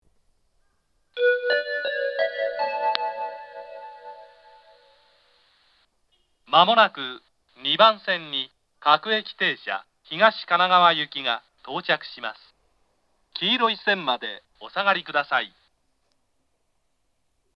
東海道型(男性)
接近放送